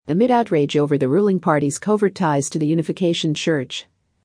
音声速度、語彙のいずれも難易度の高い上級レベルの出題でしたね。
英語ニュースの穴埋めディクテーション・クイズ